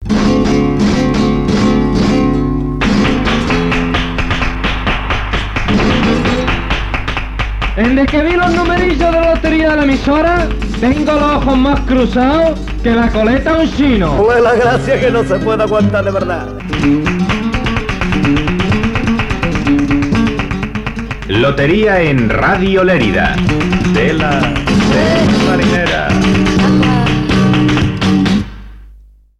Promoció de la "Lotería en Radio Lérida"